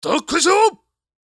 太鼓ボイス
/ F｜演出・アニメ・心理 / F-85 ｜演出用ボイス